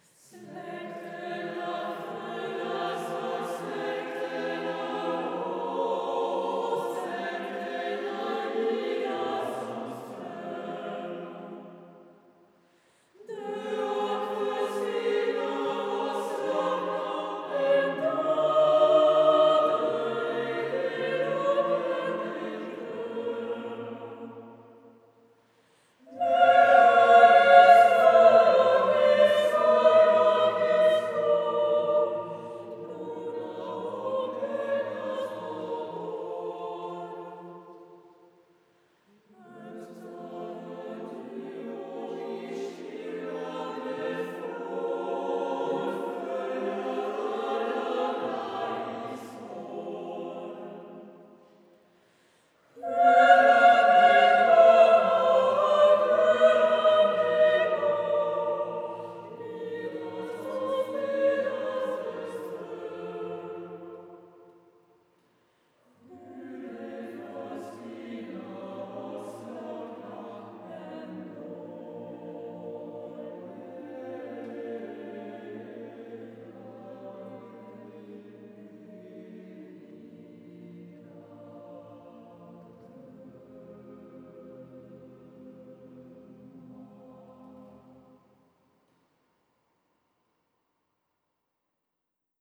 Retrouvez ici des extraits « live » de nos concerts !